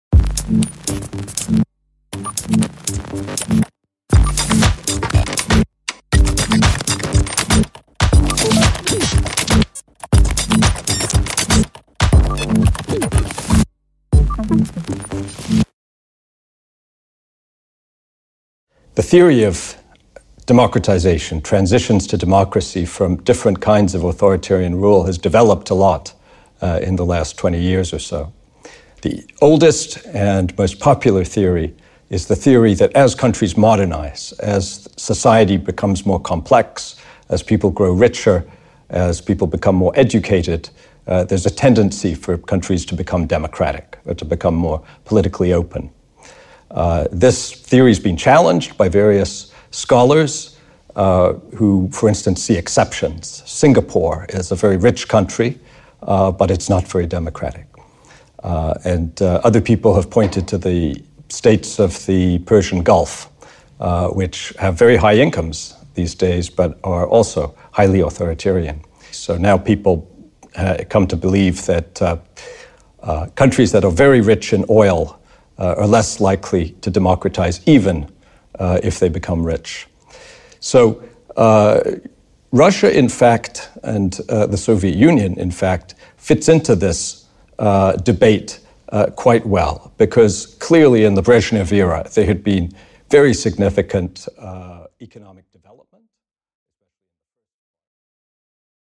Аудиокнига Особенности большого транзита в России | Библиотека аудиокниг